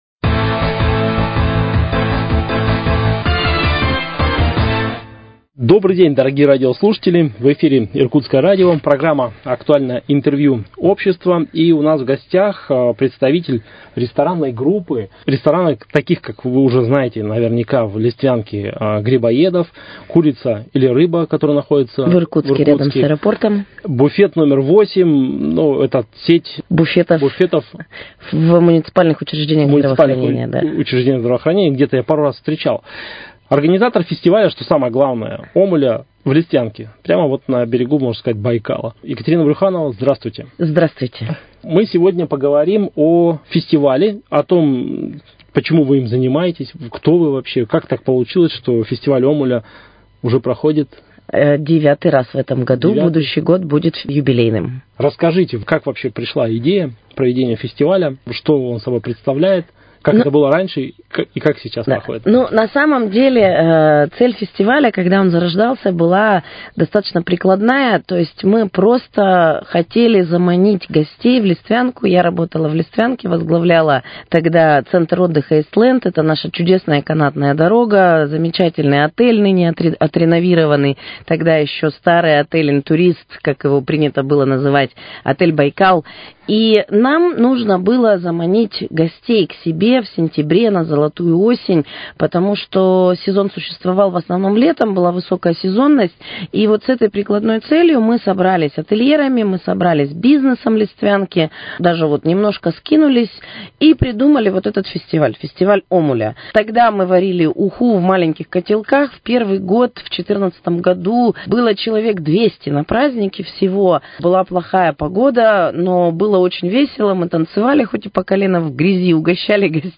Актуальное интервью: О фестивале «День омуля»